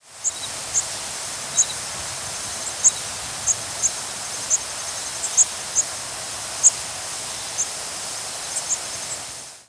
Bay-breasted Warbler diurnal flight calls
Diurnal calling sequences:
Bird in flight.